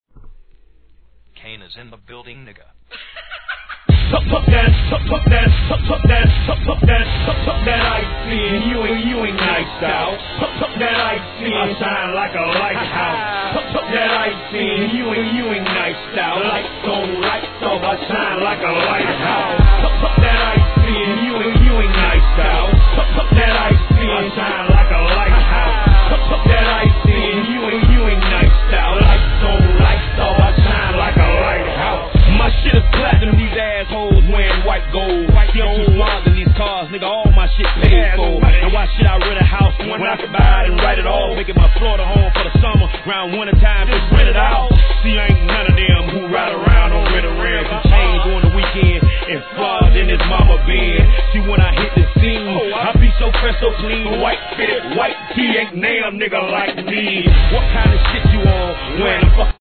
HIP HOP/R&B
CLUB映えも最高にいいでしょう!!